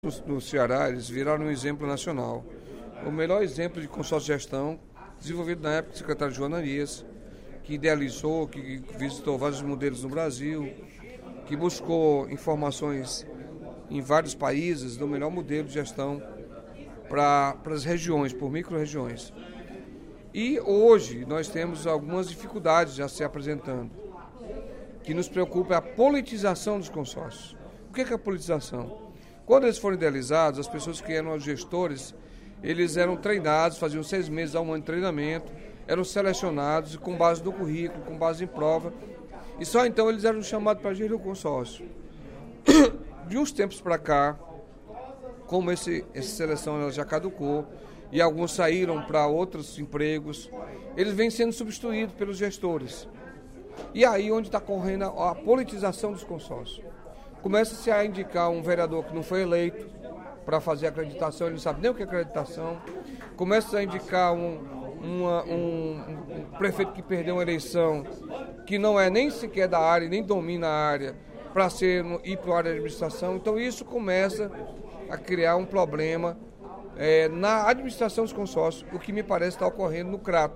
O deputado Carlos Felipe (PCdoB) criticou, nesta quarta-feira (24/05), durante o primeiro expediente da sessão plenária, a politização dos consórcios públicos na área de saúde que está ocorrendo no Ceará.